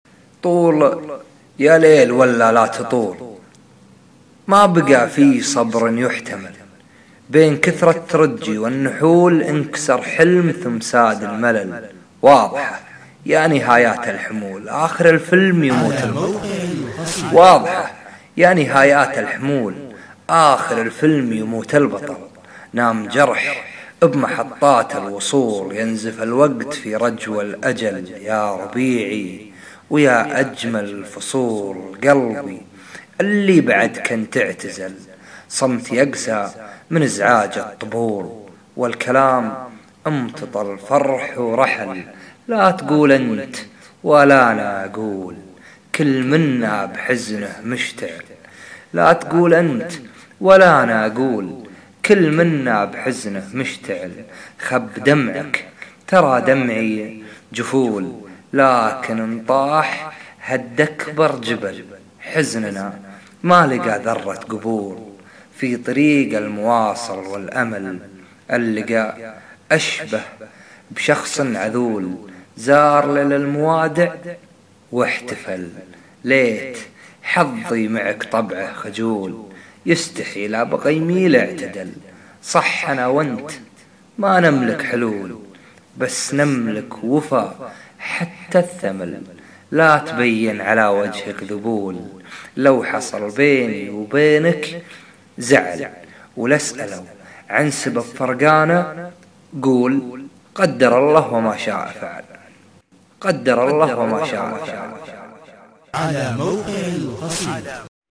wa57h_bdwn_mwsyka7.mp3